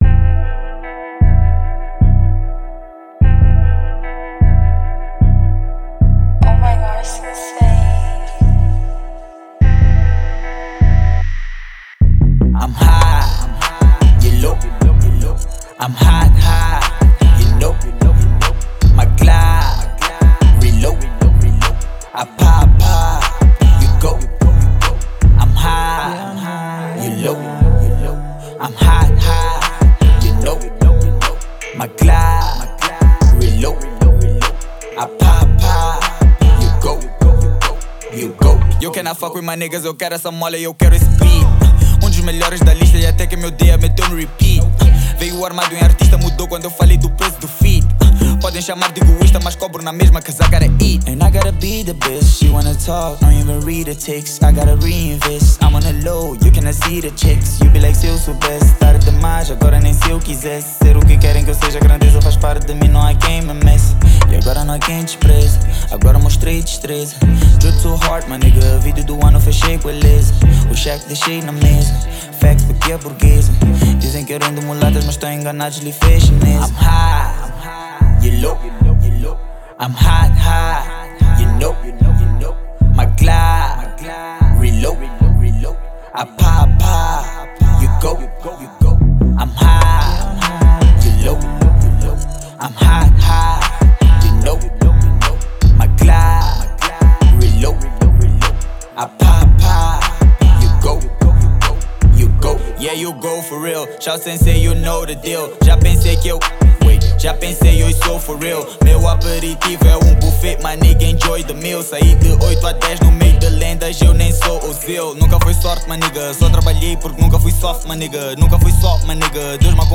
Rap
” Hip-Hop Rap 2021 ”Download Mp3” 320kbps
Uma música motivacional e em Ritmos agradáveis